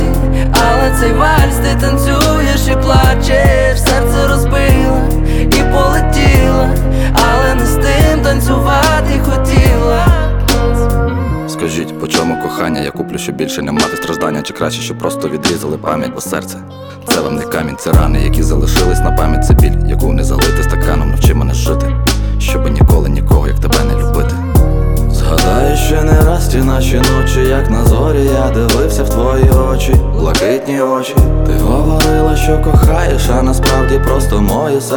Жанр: Рэп и хип-хоп / Украинские